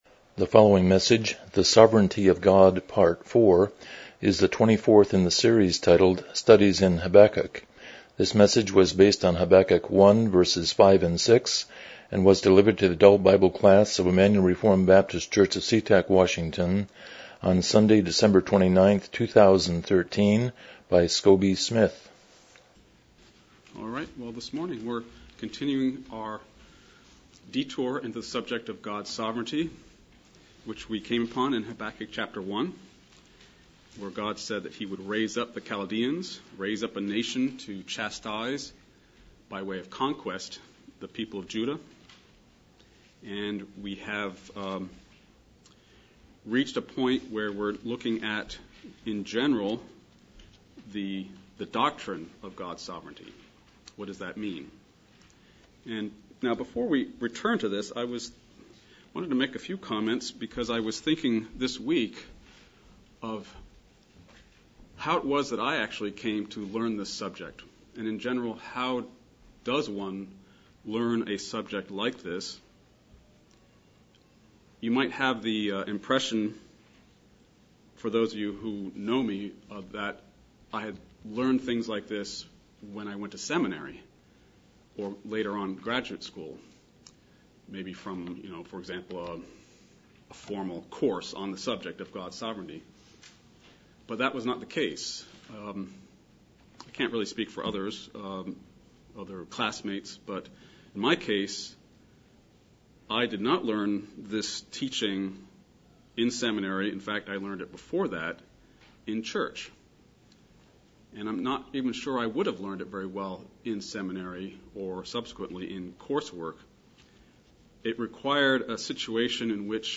Habakkuk 1:5-6 Service Type: Sunday School « 11 What Makes Man Unique?